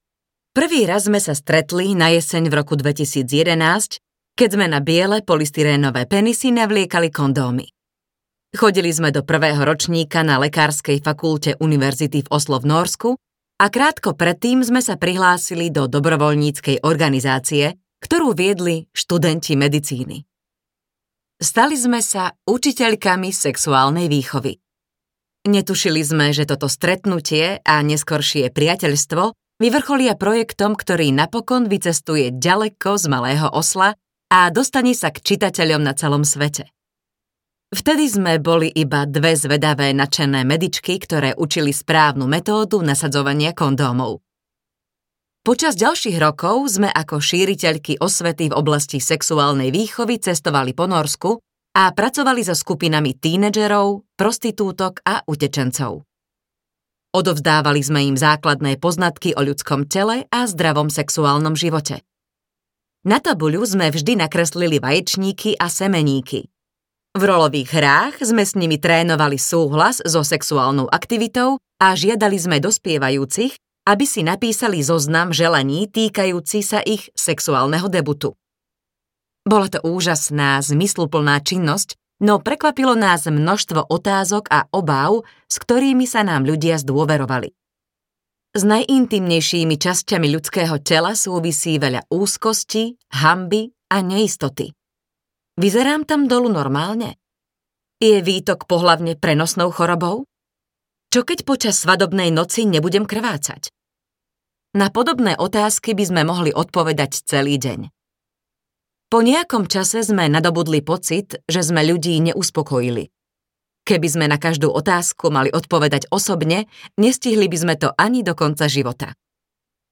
Bez hanby o ohanbí audiokniha
Ukázka z knihy